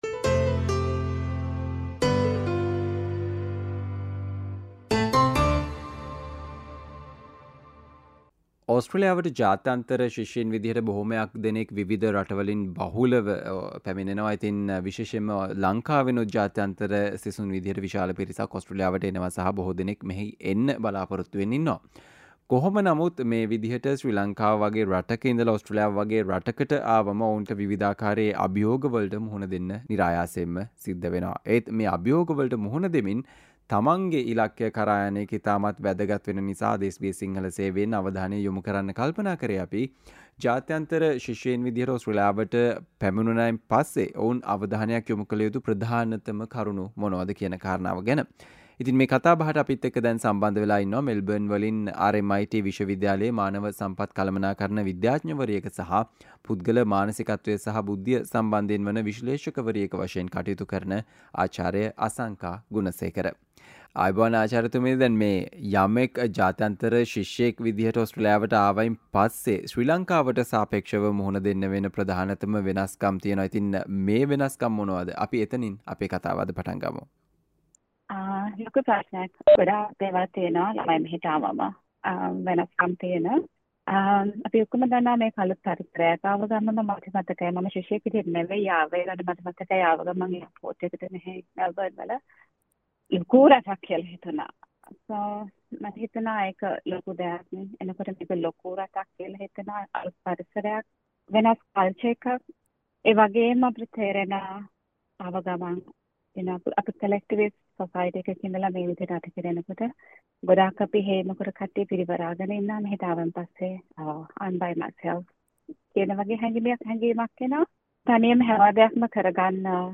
SBS සිංහල සේවය සිදු කල සාකච්චාව